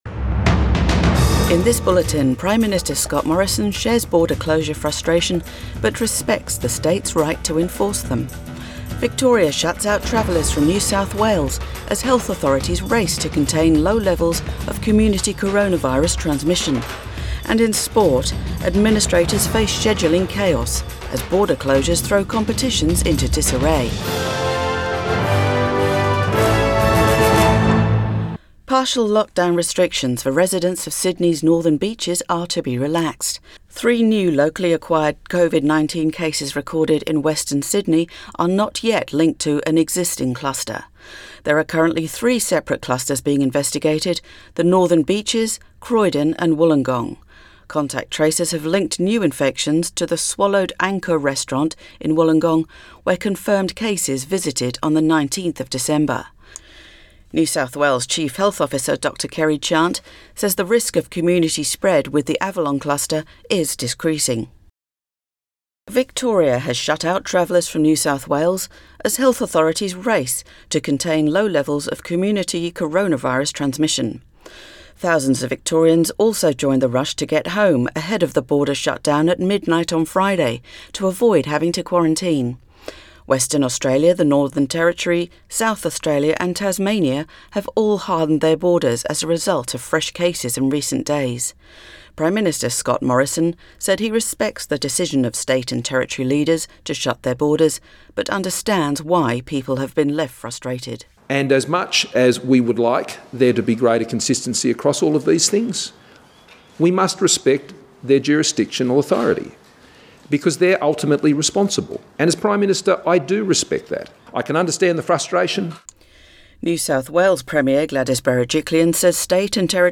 AM Bulletin 2 Jan 2021